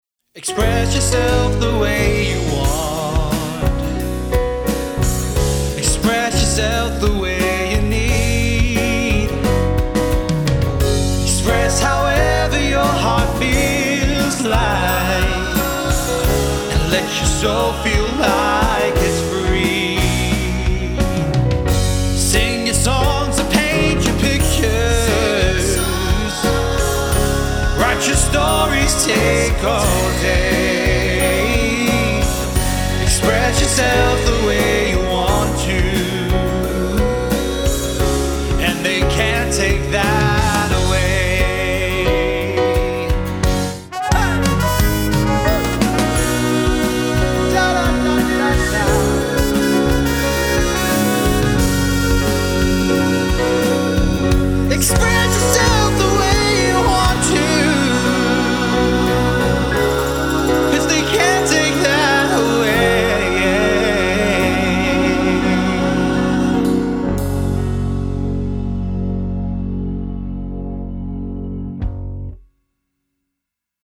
[Trumpet Solo :3]